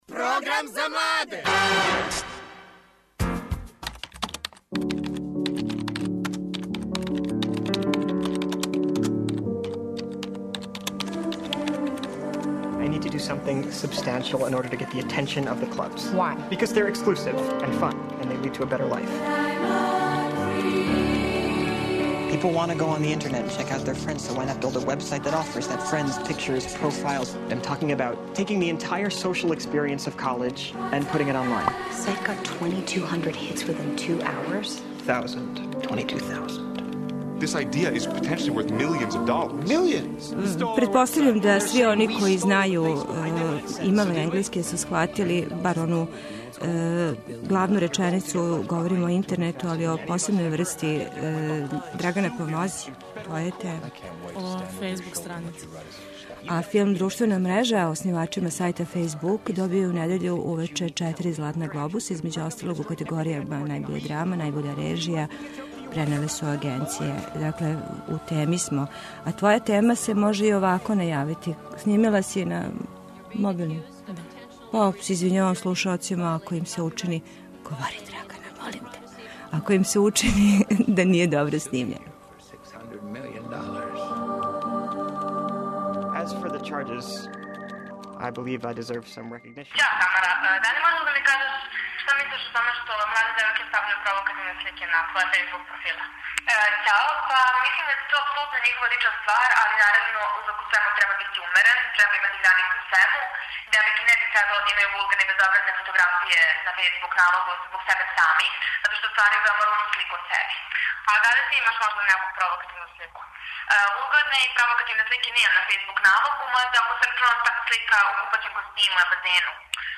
О употреби и злоупотреби интернета, говоре младе девојке које стављају фотографије на своје профиле.